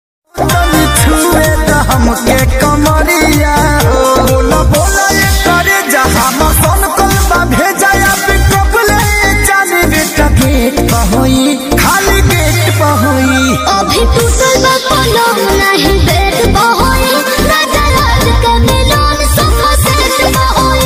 Bhojpuri ringtone